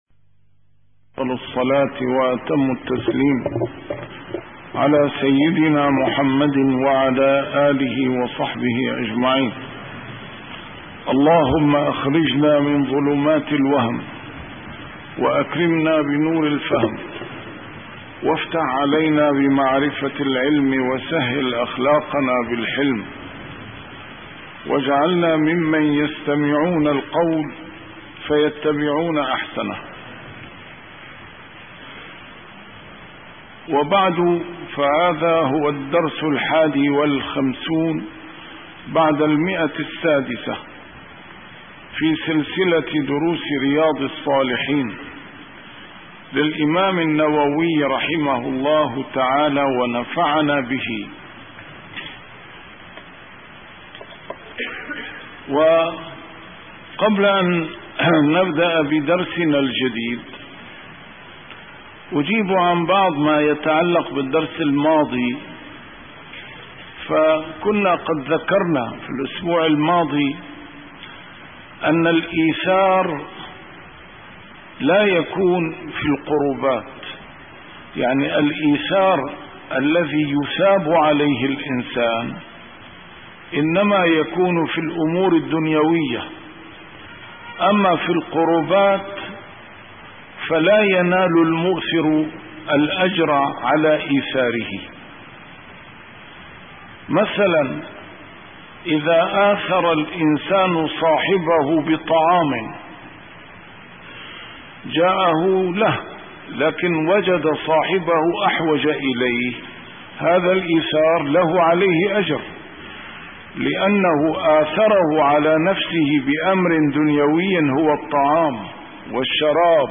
A MARTYR SCHOLAR: IMAM MUHAMMAD SAEED RAMADAN AL-BOUTI - الدروس العلمية - شرح كتاب رياض الصالحين - 651- شرح رياض الصالحين: كراهة الشرب في فم القربة